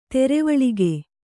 ♪ terevaḷige